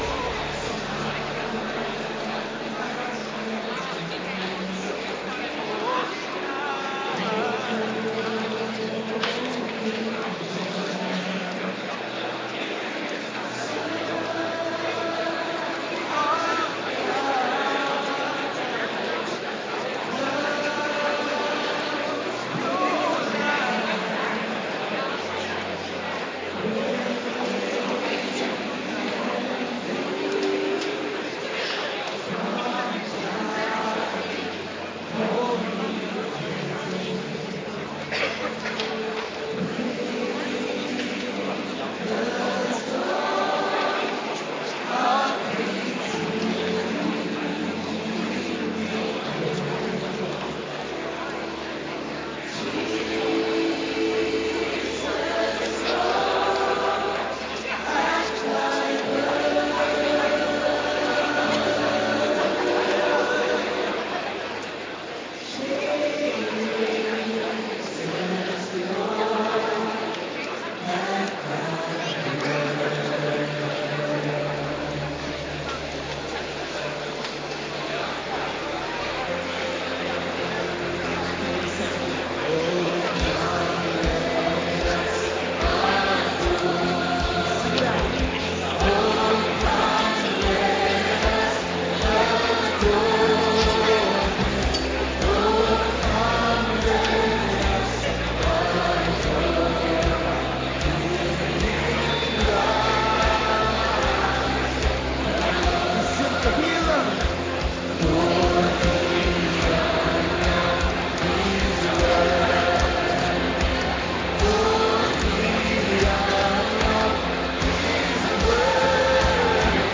Adventkerk Dinsdag week 52
m.m.v. Band Faithfull